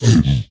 zpigangry2.ogg